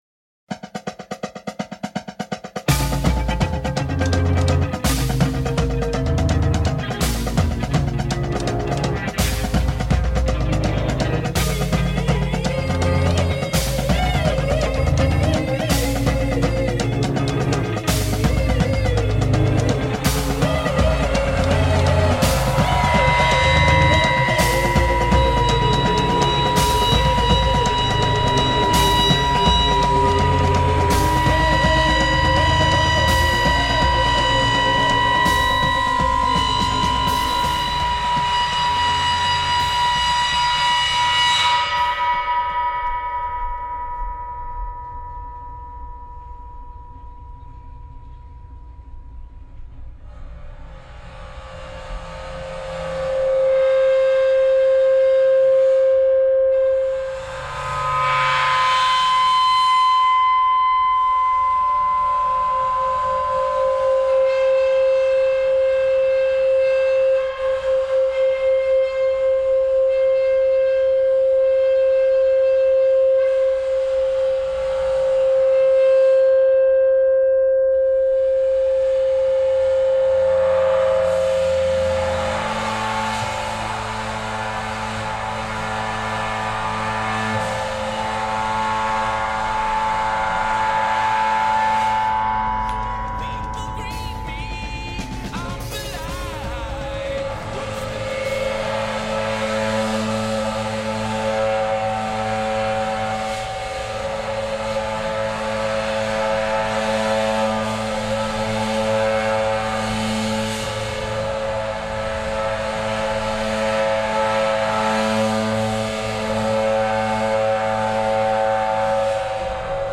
me and some samples, and toys